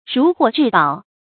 注音：ㄖㄨˊ ㄏㄨㄛˋ ㄓㄧˋ ㄅㄠˇ
如獲至寶的讀法